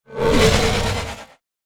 Download Monster sound effect for free.
Monster